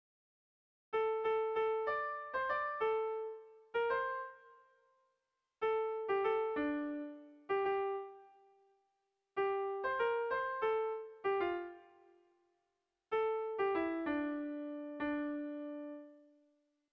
Gabonetakoa
AB